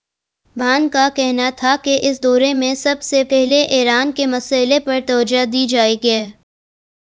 deepfake_detection_dataset_urdu / Spoofed_TTS /Speaker_01 /266.wav